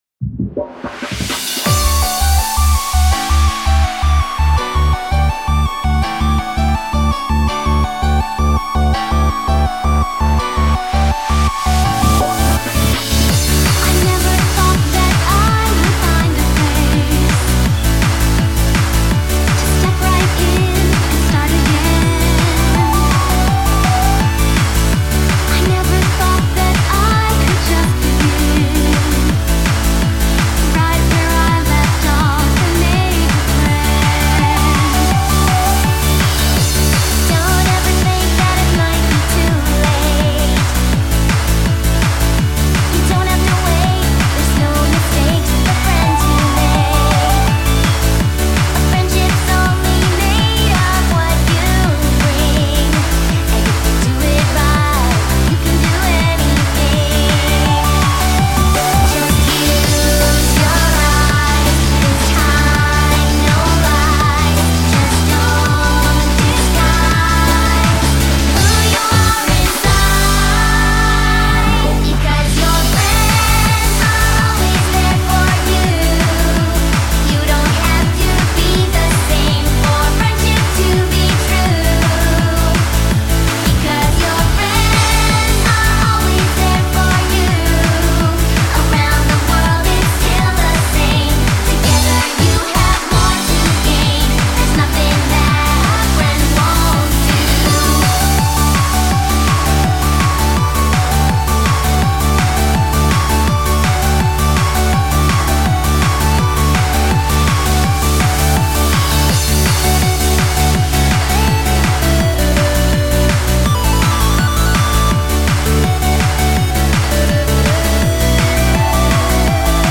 Happy Hardcore | 165 BPM | D